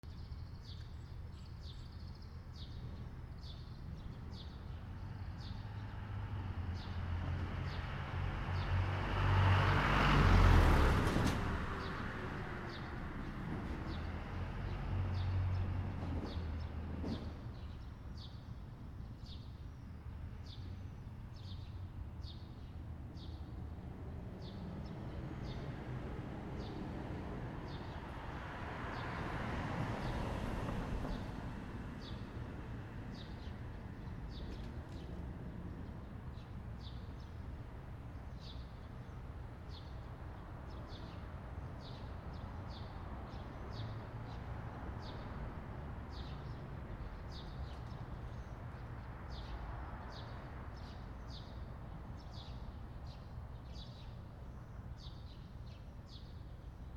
車 通過 06
/ C｜環境音(人工) / C-57 ｜再構成用_車通過